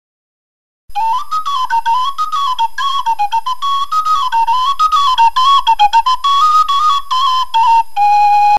URM Sonos de Sardigna: nuovi strumenti - Pipaiolu cun crocoriga
PipaioluCunCrocoriga.mp3